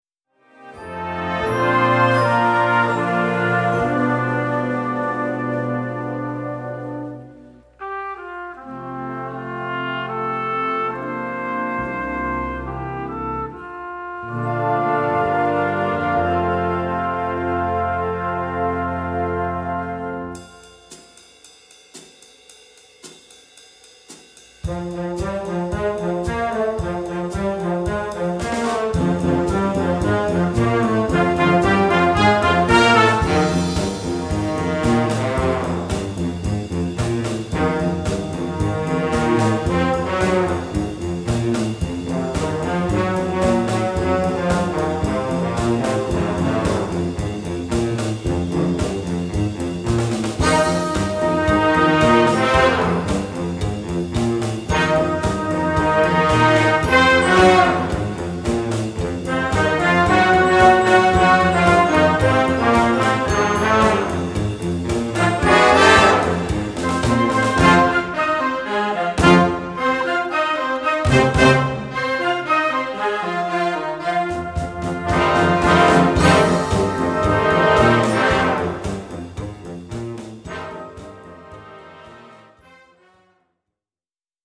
Gattung: Medley für Jugendblasorchester
Besetzung: Blasorchester